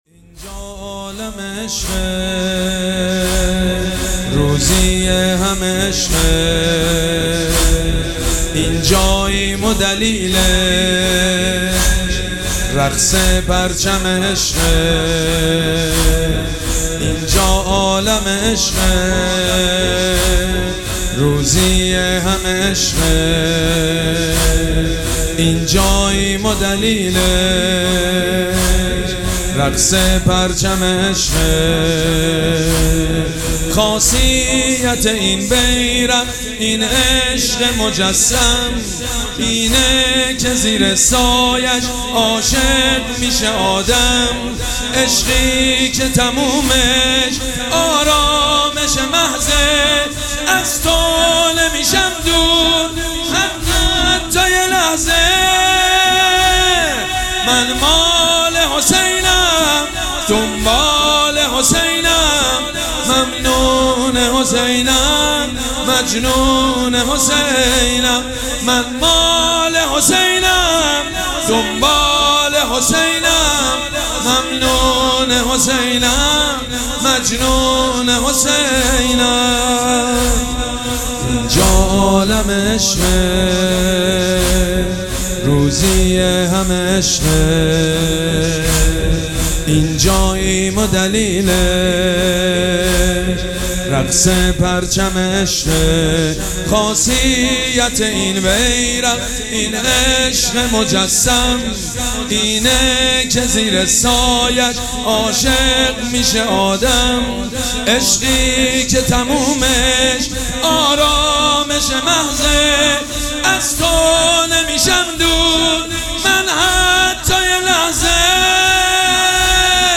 شب چهارم مراسم عزاداری اربعین حسینی ۱۴۴۷
حاج سید مجید بنی فاطمه